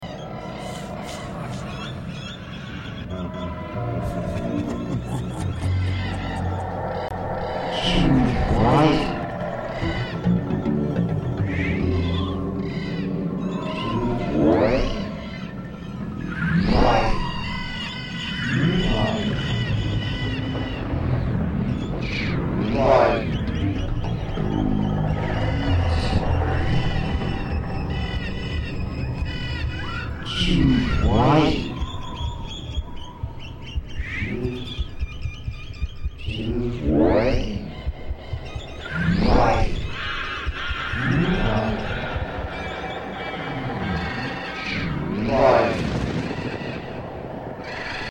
1 halloween sounds (haunted forest) + edit